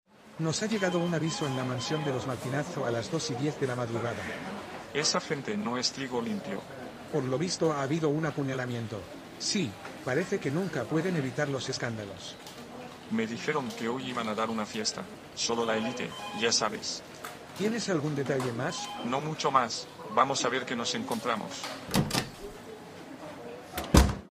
Conversación policías
1-conversacion-policias.mp3